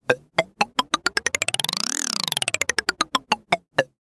wheel-spin.mp3